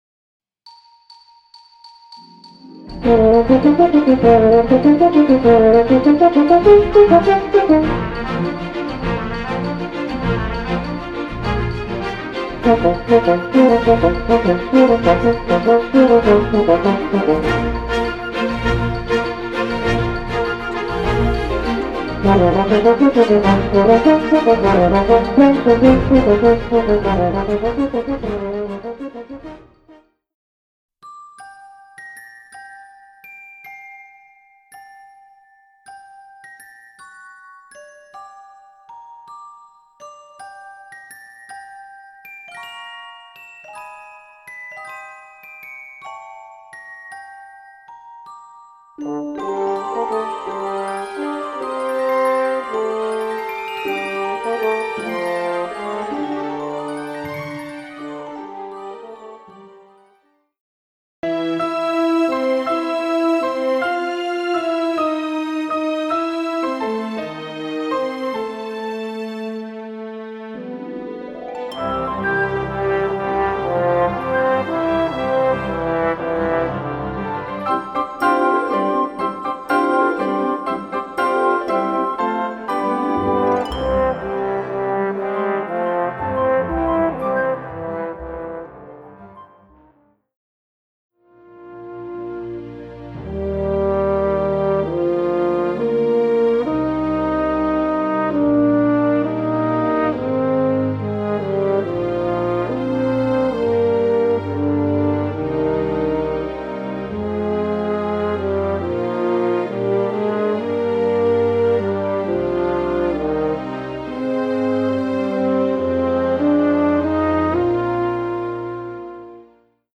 Voicing: French Horn w/ Audio